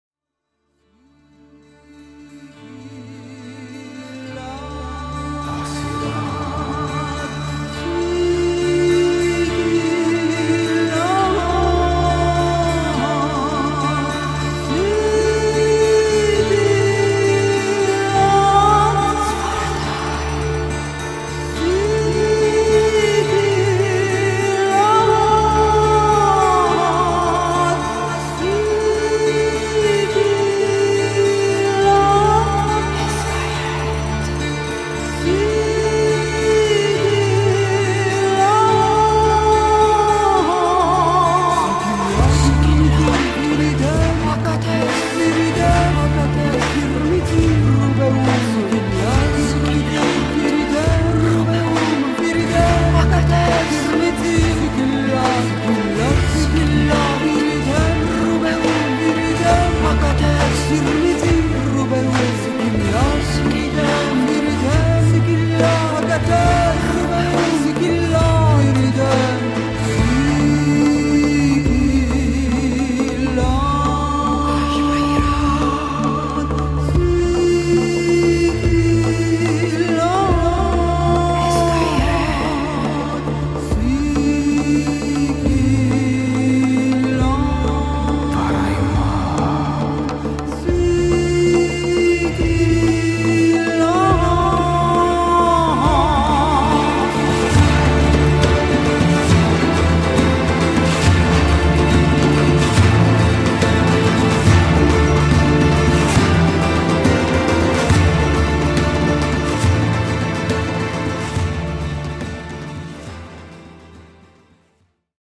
vocals, flute
classic, acoustic and electric guitars, chitarra battente
keyboards